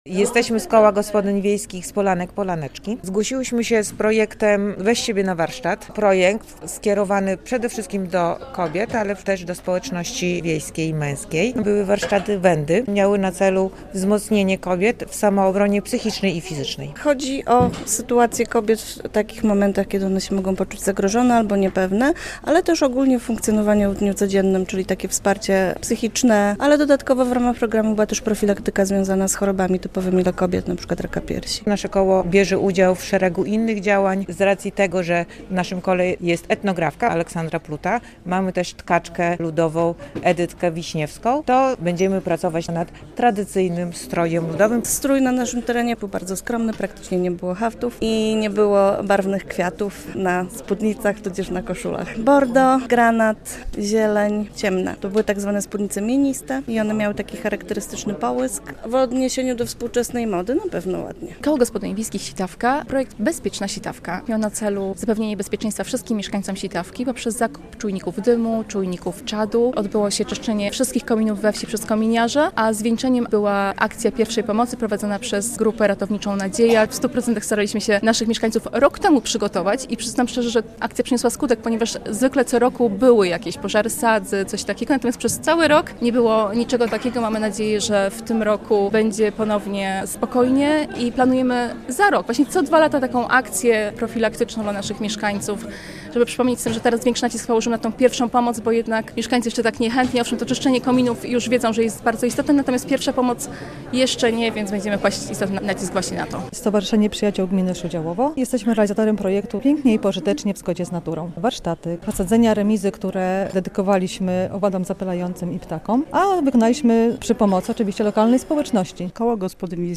Aktywność mieszkańców podlaskiej wsi. Jakie mają pomysły? - relacja